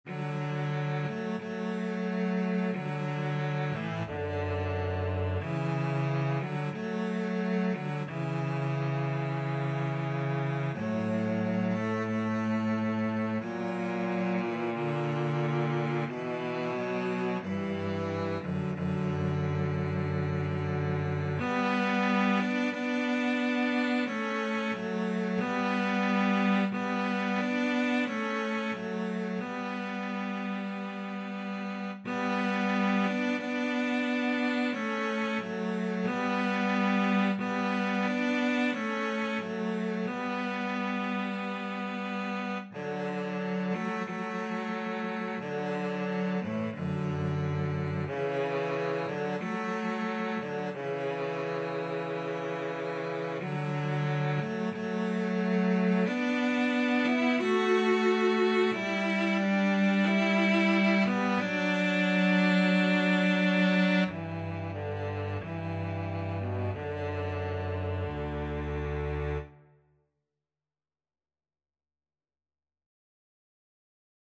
CelloDouble BassDouble Bass (Bass Clef)
=180 Largo
Classical (View more Classical Cello-Bass Duet Music)